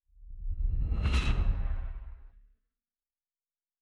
Distant Ship Pass By 7_1.wav